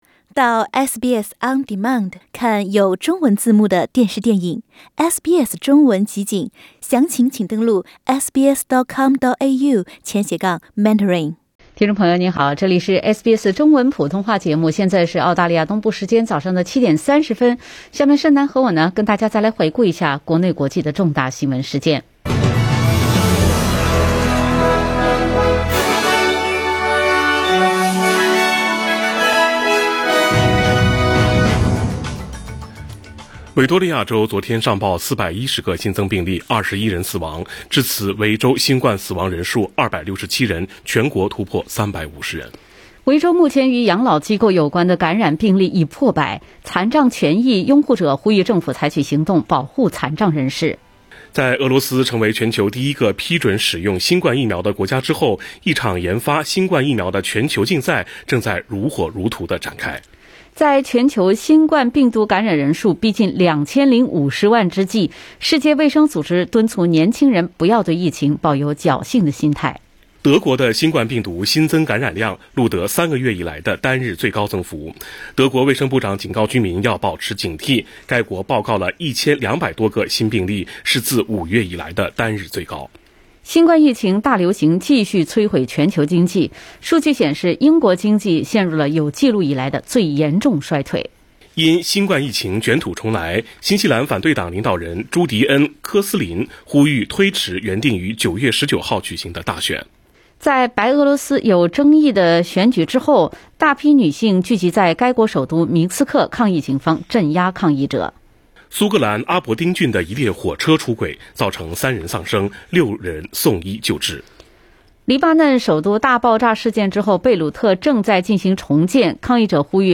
SBS早新闻（8月13日）